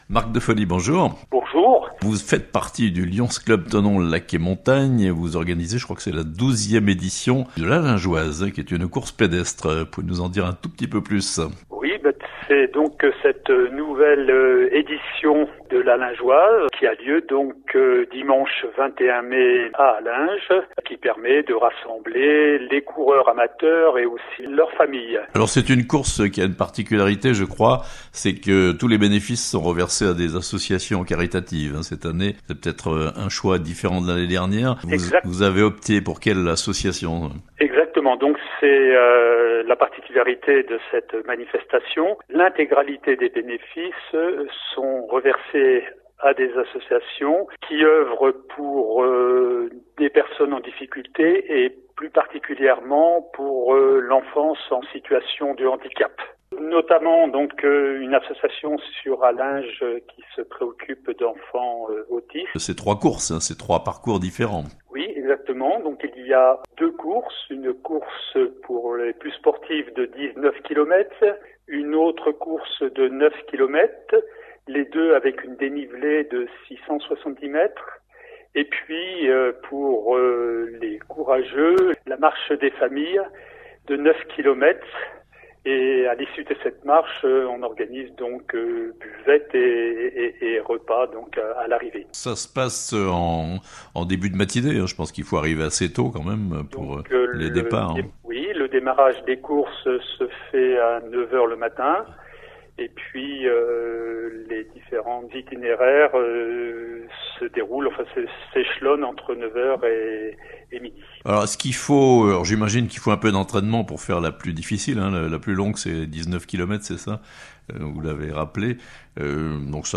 L'Allingeoise, une course pédestre à but caritatif (interview)